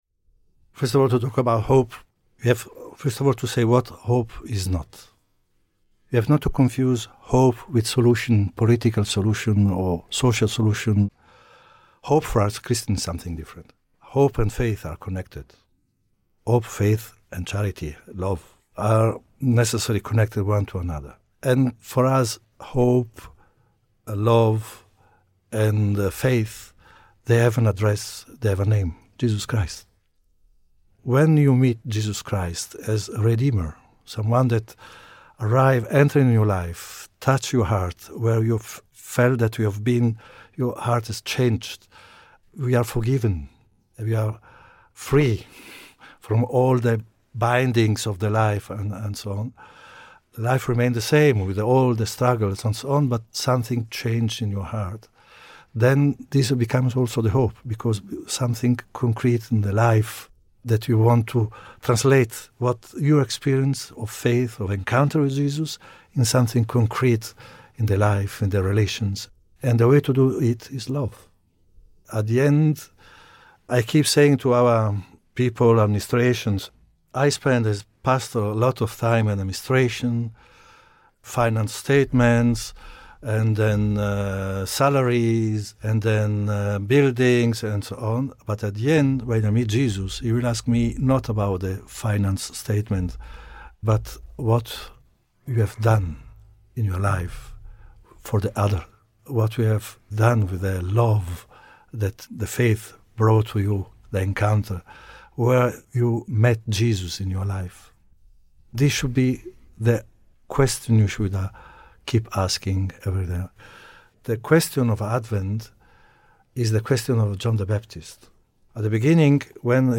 Advent reflection from the Latin Patriarch of Jerusalem
The Latin Patriarch of Jerusalem, His Beatitude Pierbattista Pizzaballa, took some time out of his very busy schedule to be guest of honour in a joint Catholic News / Middle East Analysis podcast just before the First Sunday of Advent.